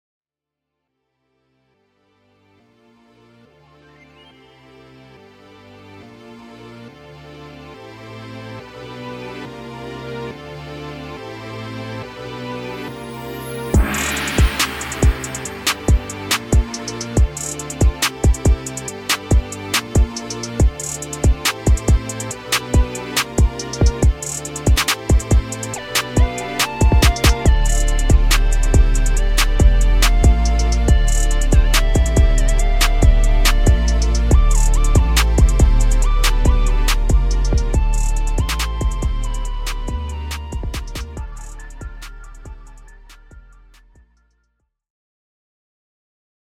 Sample Instrumental MP3